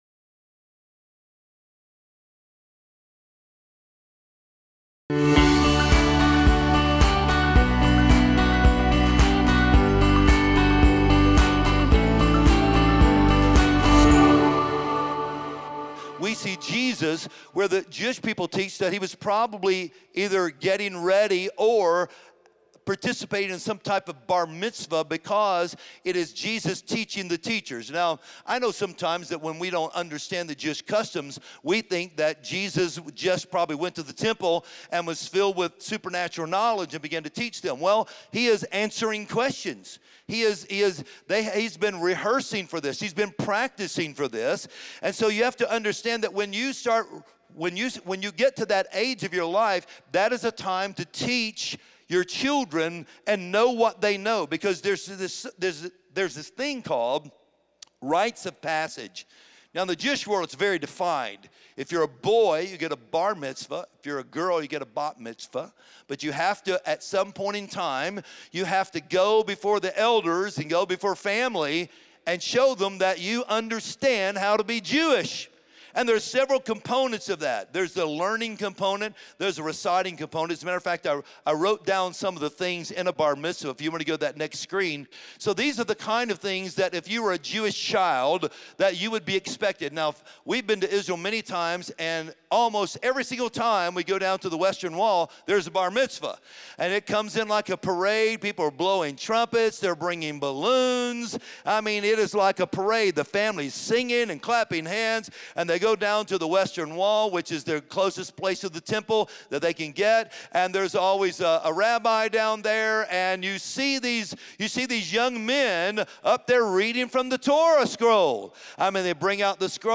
Join us this week for the sermon “Train Up Your Children.”